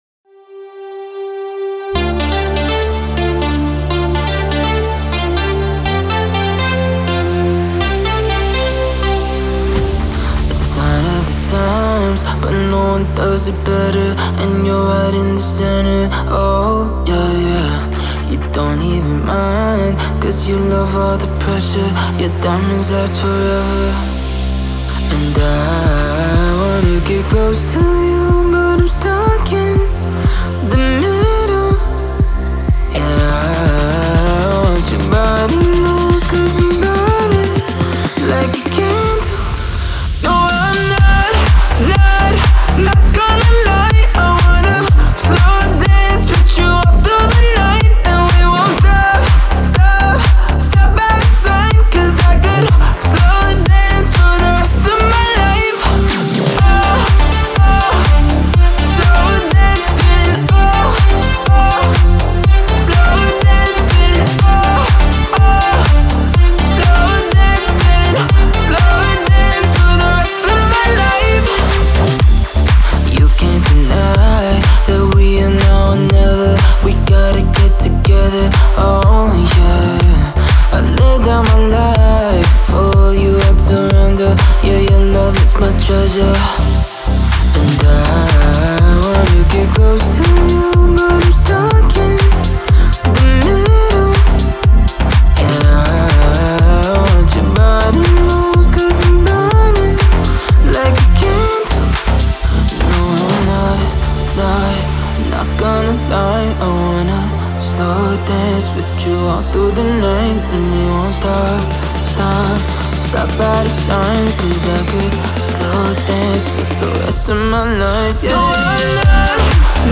это атмосферная композиция в жанре электронной музыки